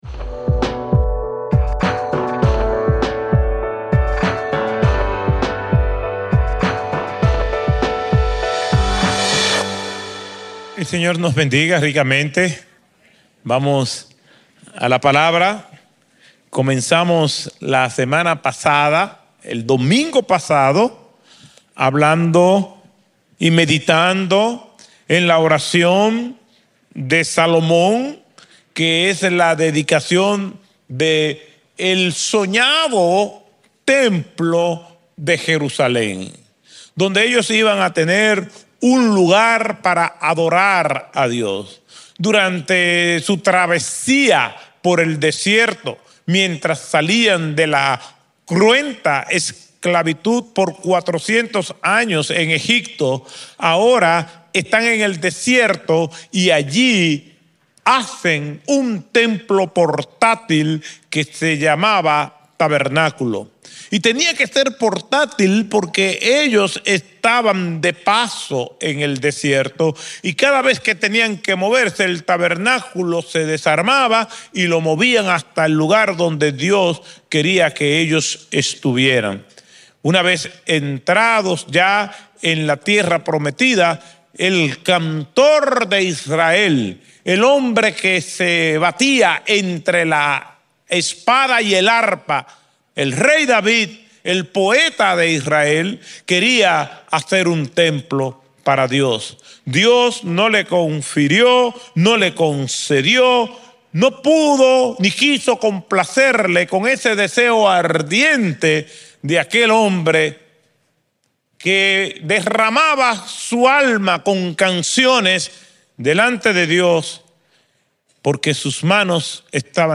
Un mensaje de la serie "Temas libres."